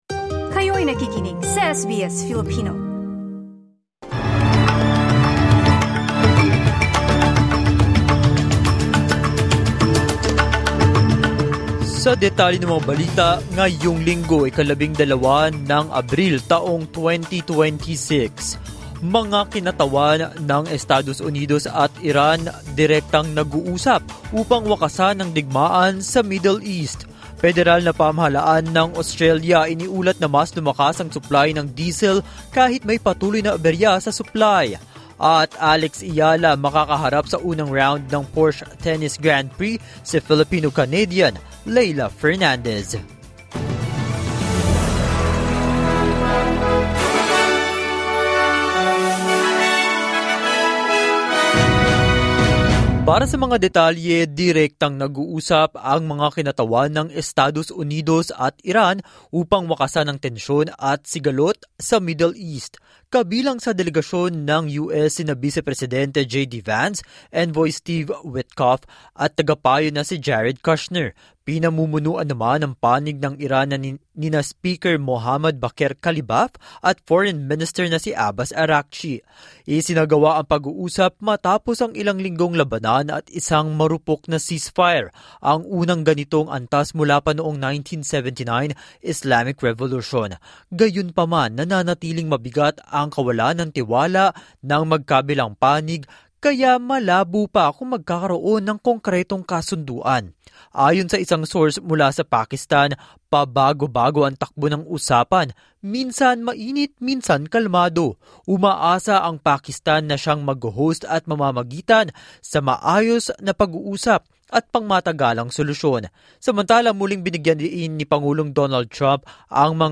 SBS News in Filipino, Sunday 12 April 2026